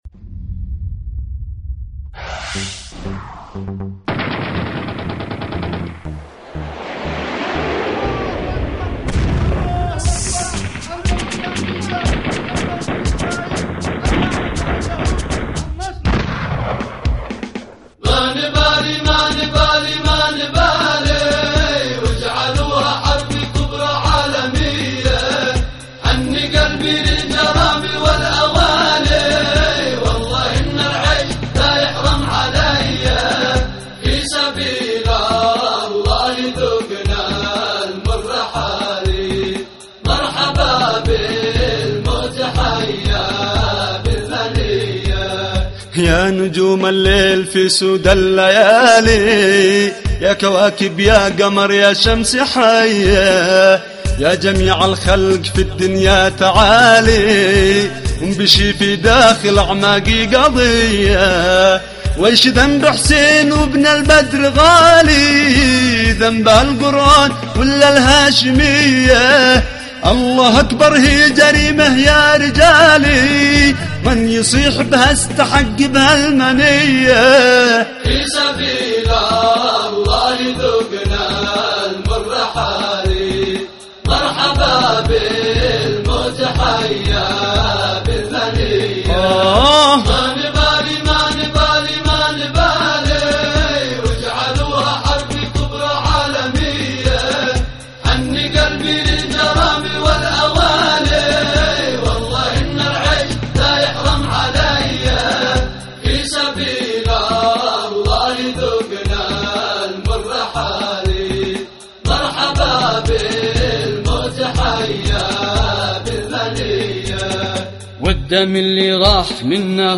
اناشيد يمنية